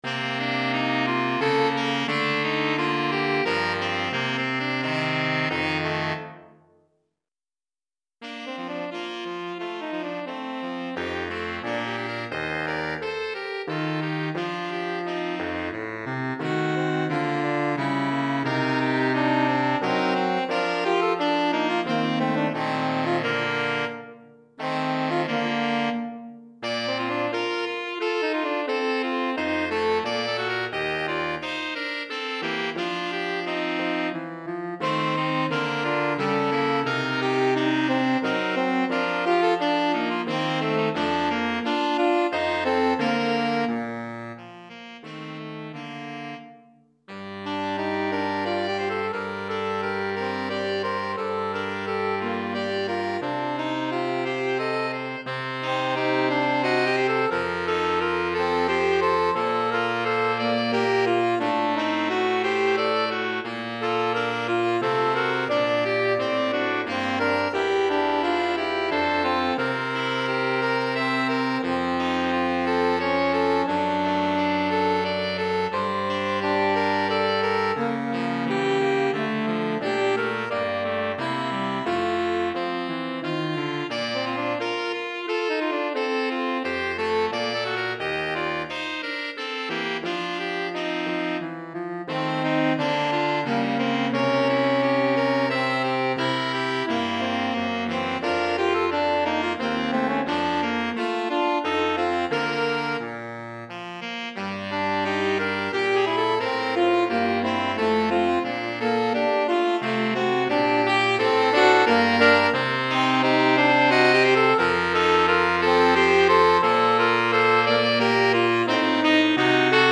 3 Clarinettes Sib et Clarinette Basse